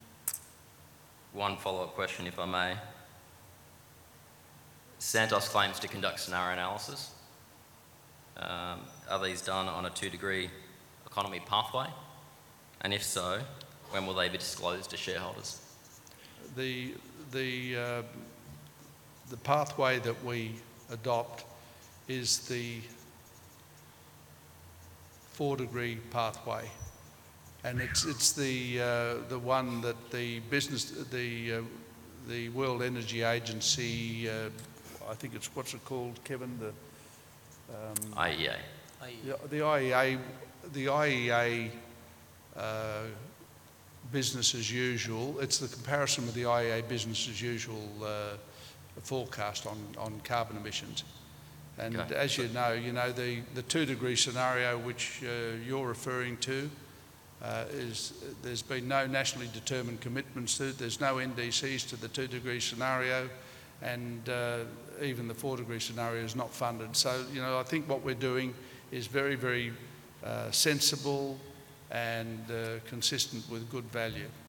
Adelaide, Thursday, 4 May 2017